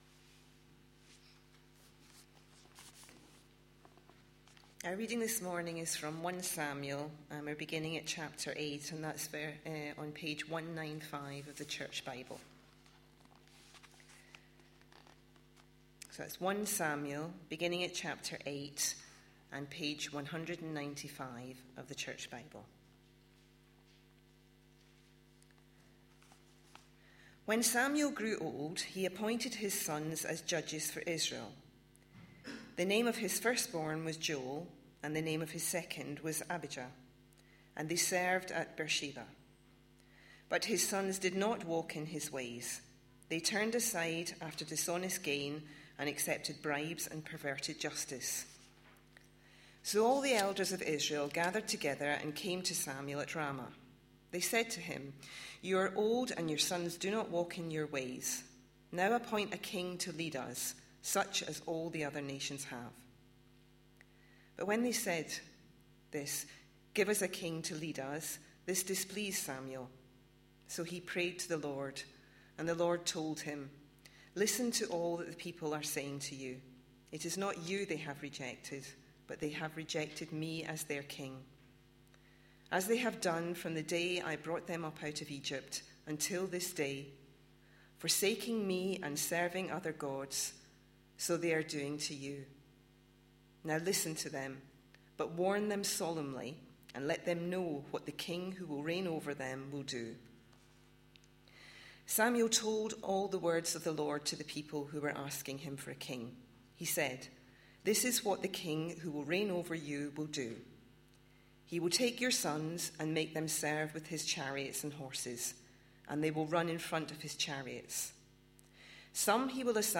A sermon preached on 28th April, 2013, as part of our God's King? series.